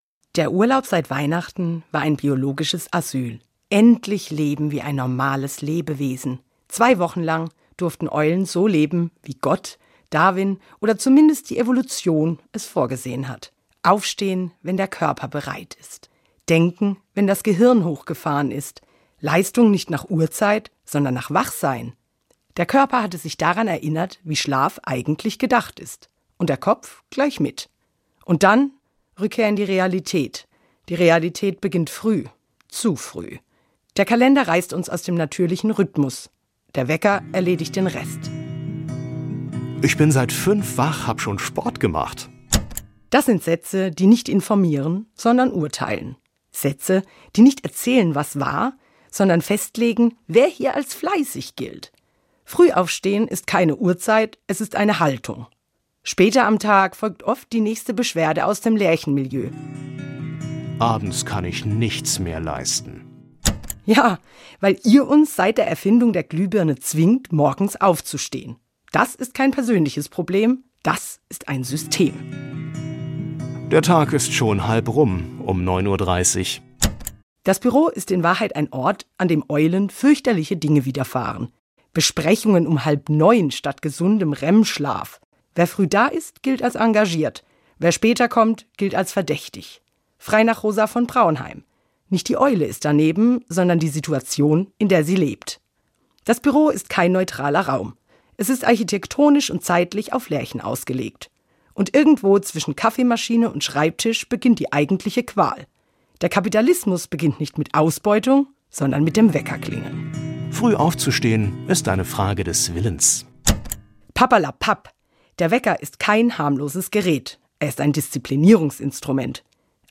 Glosse
glosse-diktatur-der-lerchen.mp3